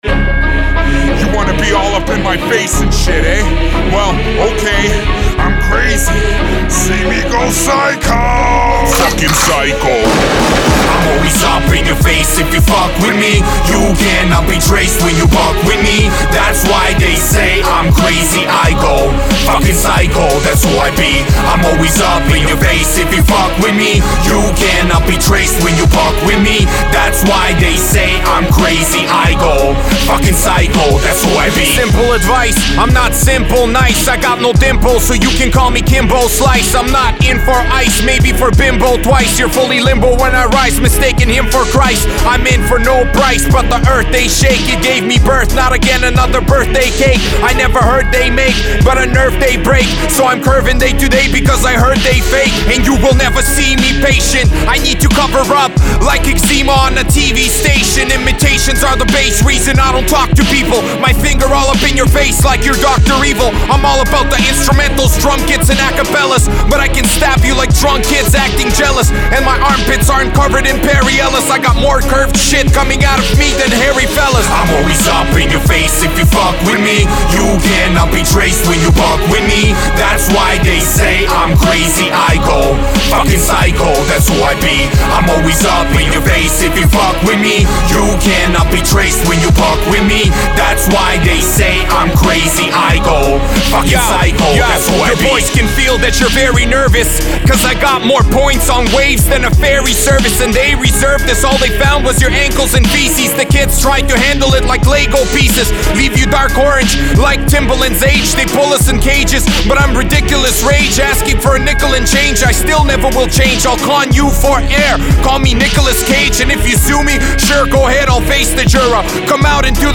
Зарубежные Rap/Hip-Hop [64]